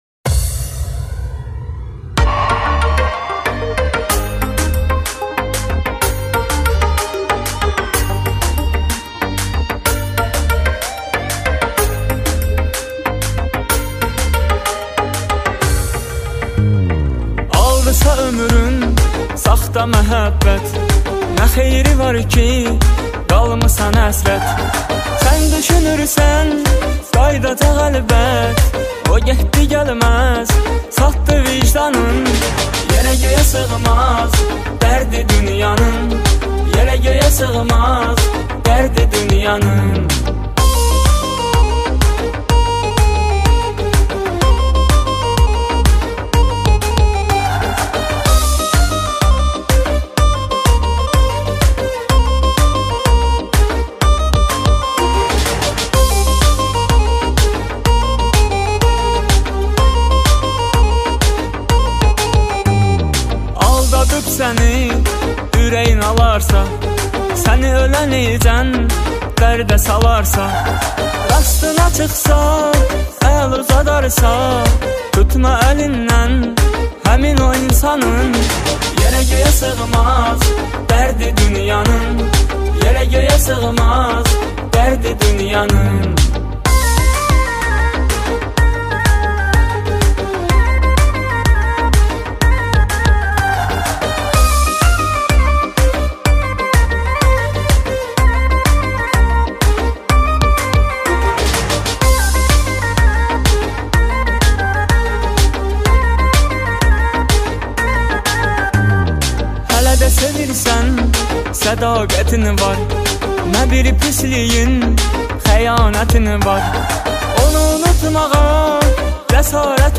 Жанр: Турецкая музыка